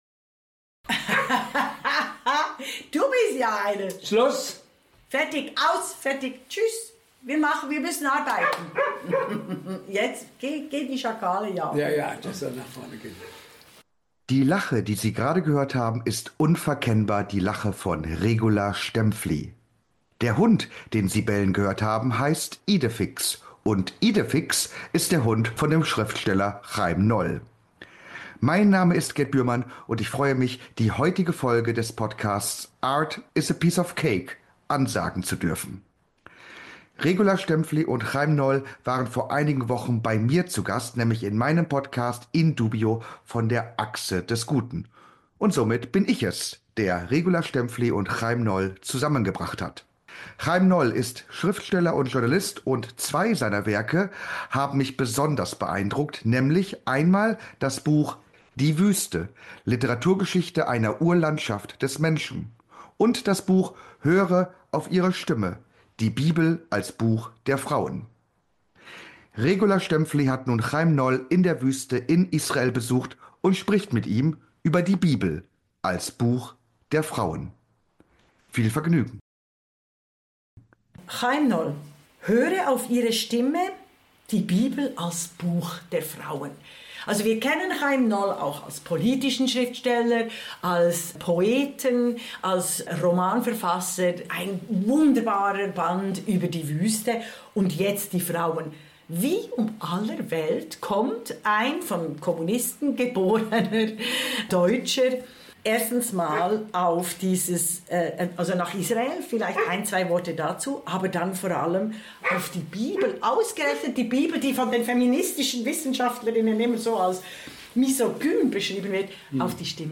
"Höre auf Ihre Stimme" über die Bibel als Buch der Frauen. Regula Staempfli in der Wüste Negev zu Besuch beim israelischen Journalisten & Schriftsteller Chaim Noll im April 2025.